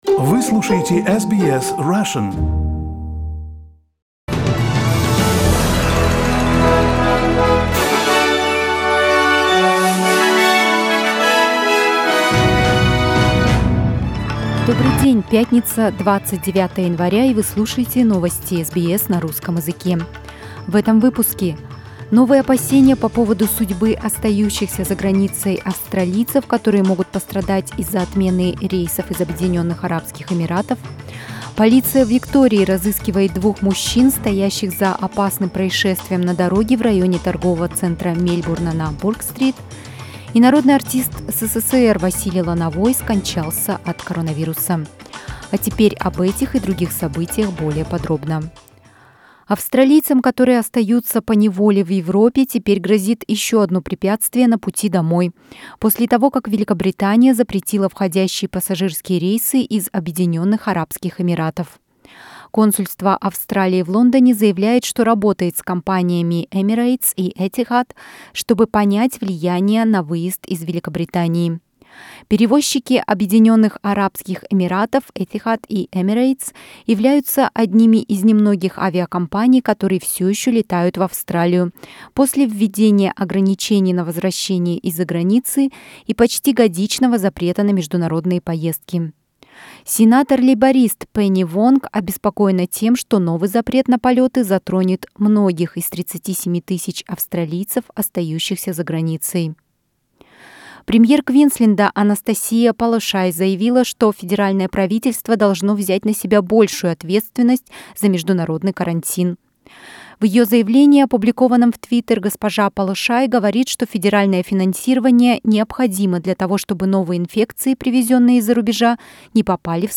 News Bulletin in Russian, January 29